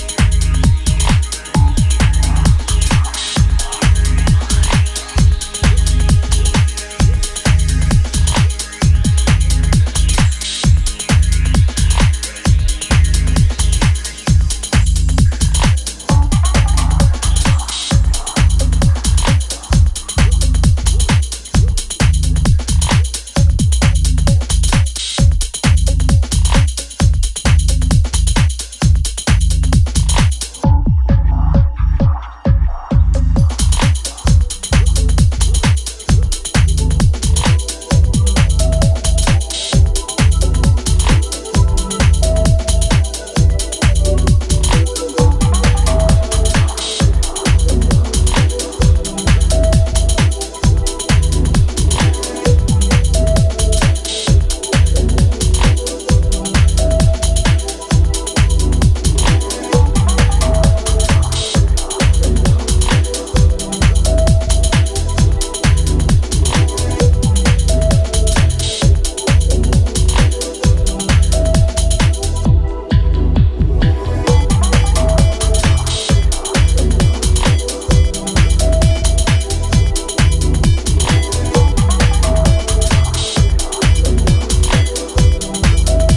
techno. With pulsating beats and intricate soundscapes